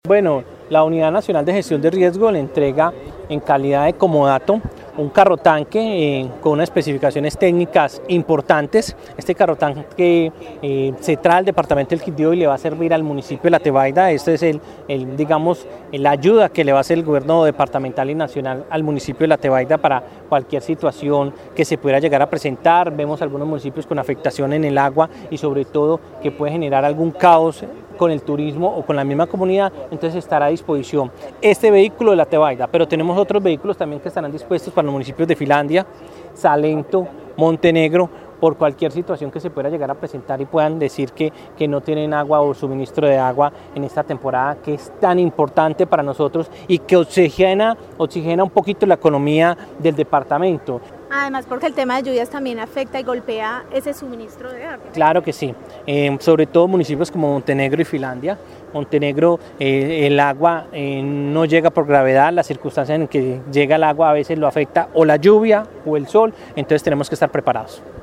Secretario del Interior del Quindío sobre entrega de carrotanque